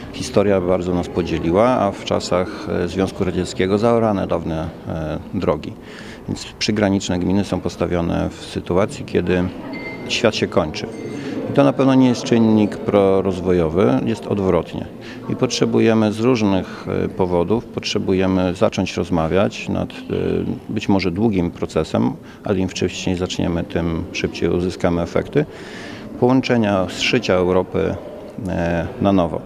Jak wyjaśnia Arkadiusz Nowalski, burmistrz Sejn, peryferyjne położenie i zatarcie dawnych połączeń komunikacyjnych nie pomaga w rozwoju.